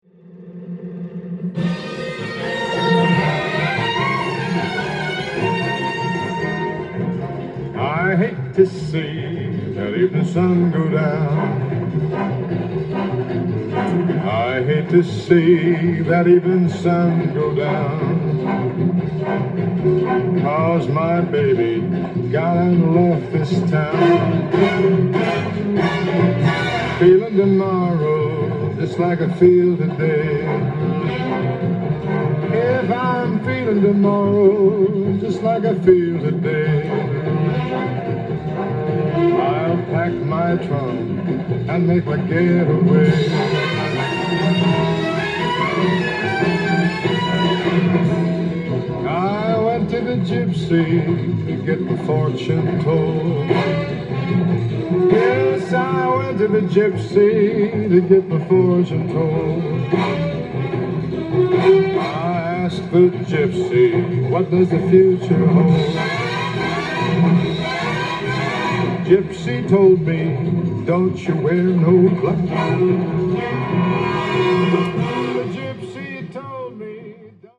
LP
店頭で録音した音源の為、多少の外部音や音質の悪さはございますが、サンプルとしてご視聴ください。